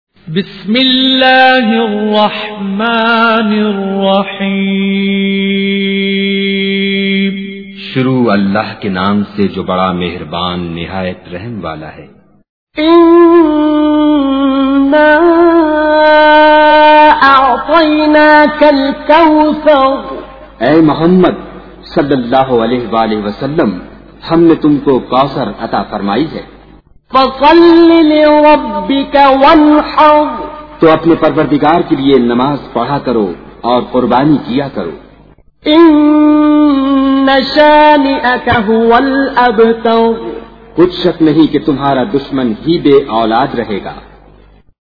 تلاوت بااردو ترجمہ